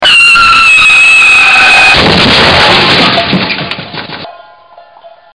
cars.wav